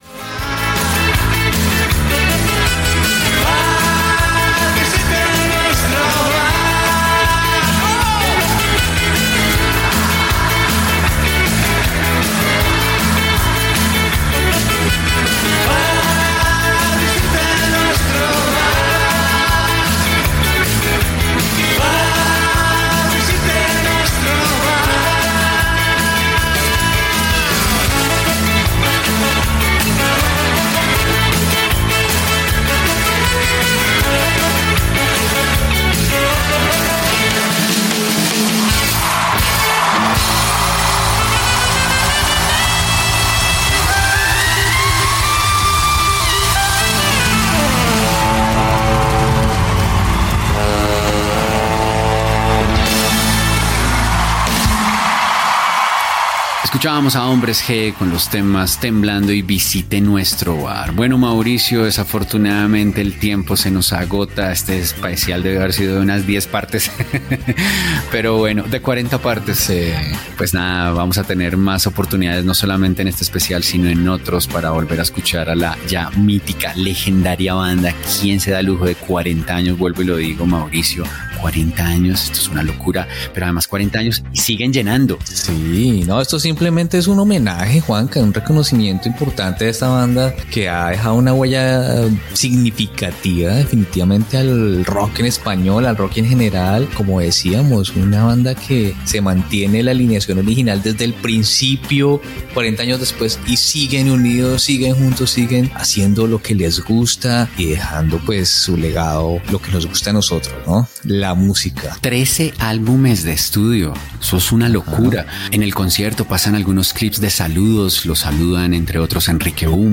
Comiat del programa dedicat a Hombres G. Indicatiu de la ràdio
Musical